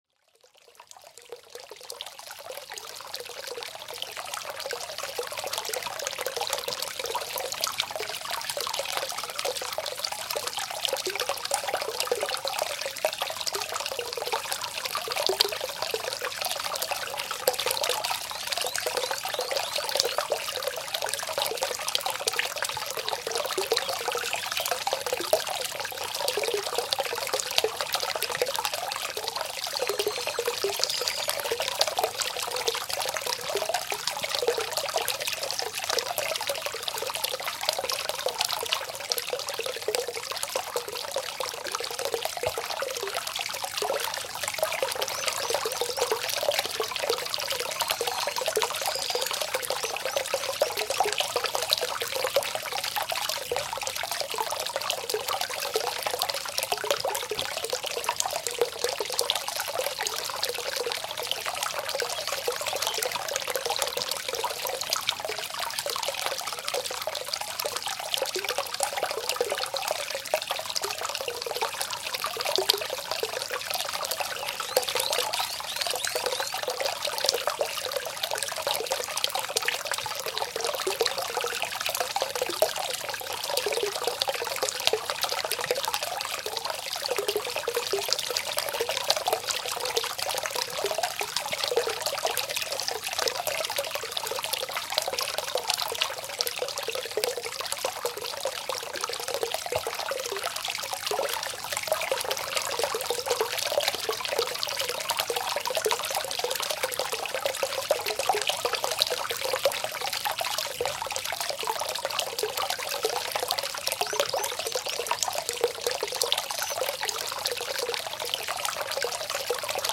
Onsen-Sound Sanftes Wasser & Naturgeräusche | Stressabbau & Entspannung